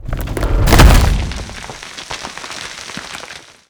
step1.wav